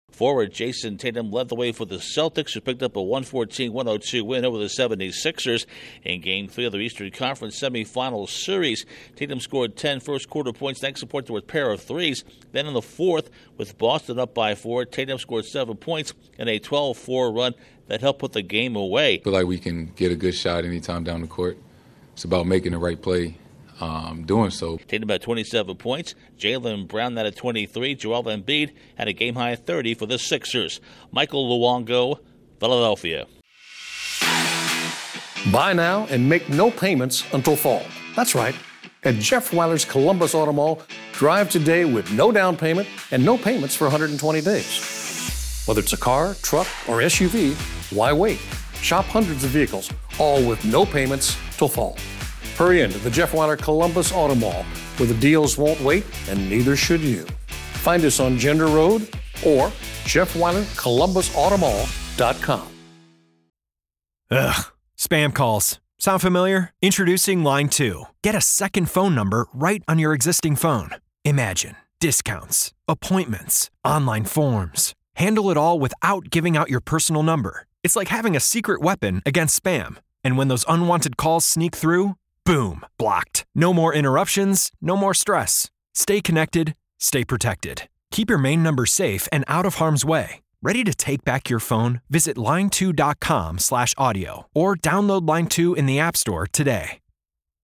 The Celtics take a 2-1 lead in the Eastern Conference semifinals. Correspondent